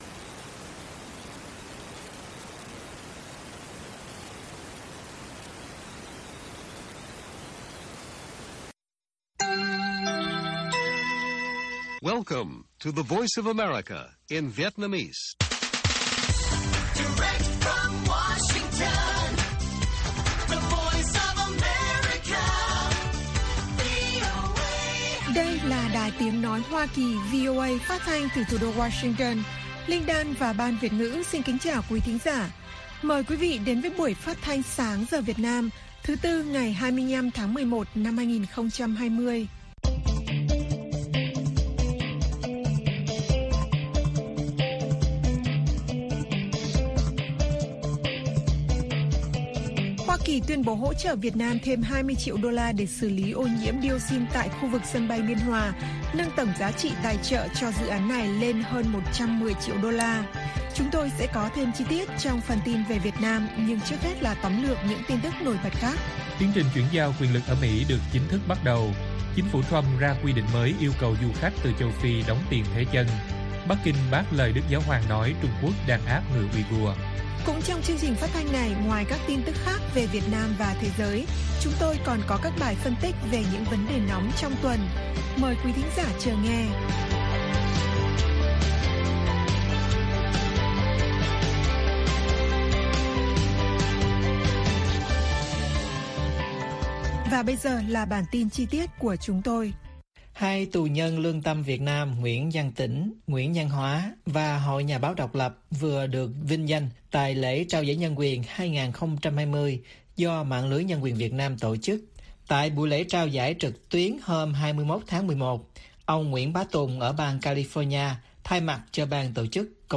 Bản tin VOA ngày 25/11/2020